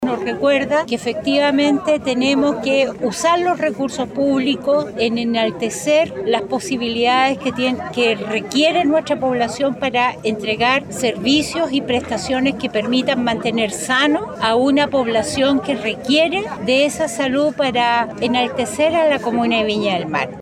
Al respecto, la Directora del Servicio de Salud Viña del Mar-Quillota, Andrea Quiero, señaló que hay que utilizar los recursos públicos para enaltecer las posibilidades que requiere la población.